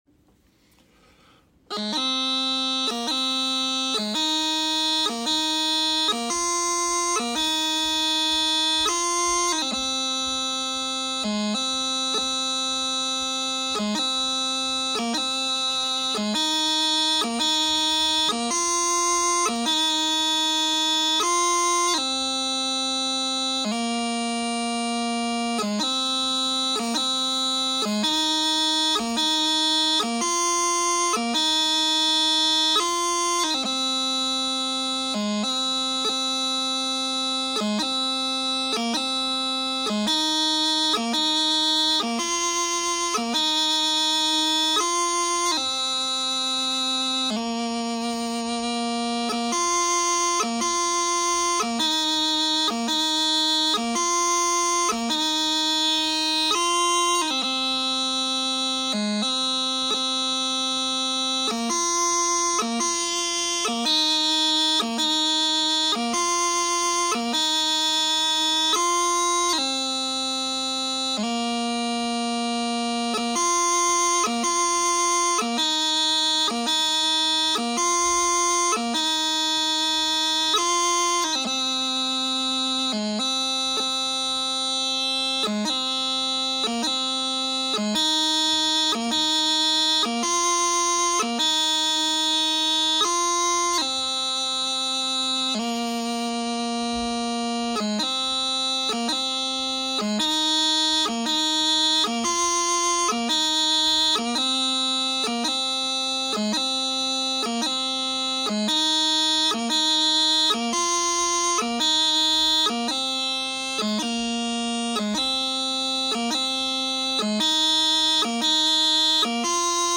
Below the tune I play three excerpts on the practice chanter to let people hear how it goes.